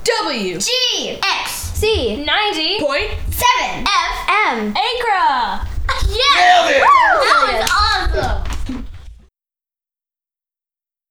WGXC Legal Station ID (Audio)